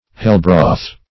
Search Result for " hellbroth" : The Collaborative International Dictionary of English v.0.48: Hellbroth \Hell"broth`\, n. A composition for infernal purposes; a magical preparation.
hellbroth.mp3